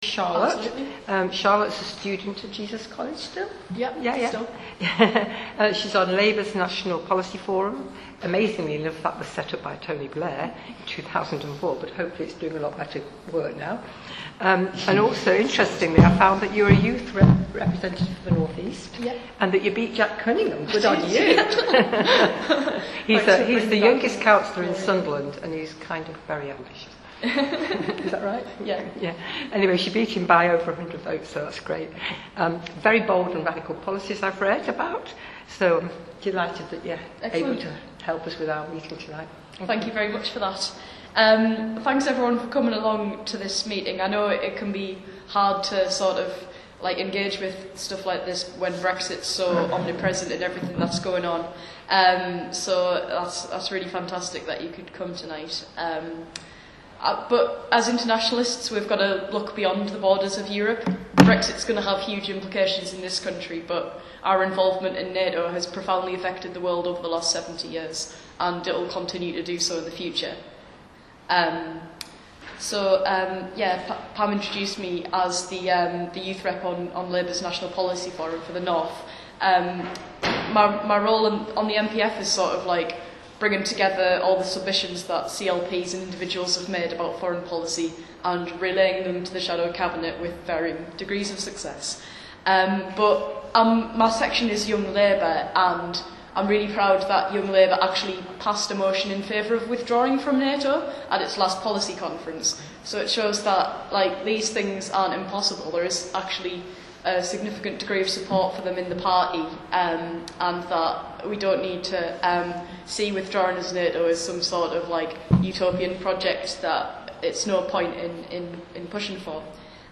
Report of Public Meetings on NATO @70
On Thursday April 4 2019, on the 70th anniversary of the Founding of NATO a public meeting was held in Newcastle.